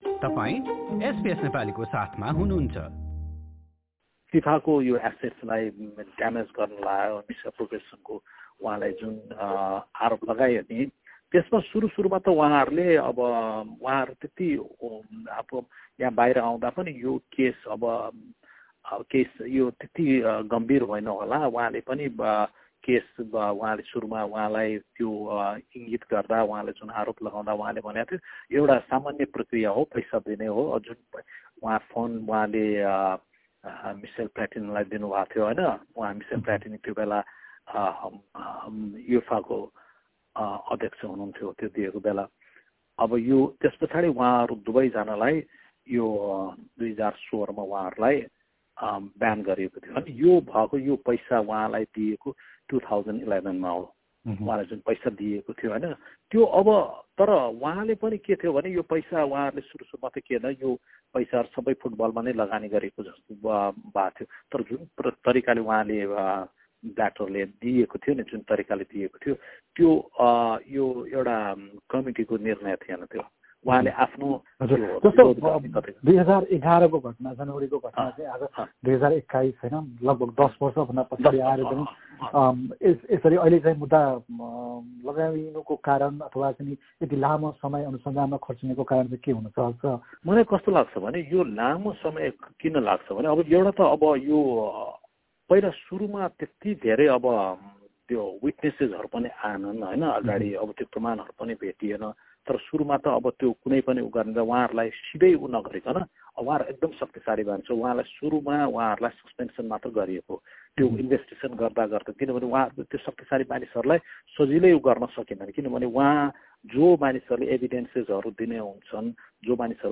यस विषयलाई लिएर गरेको कुराकानी।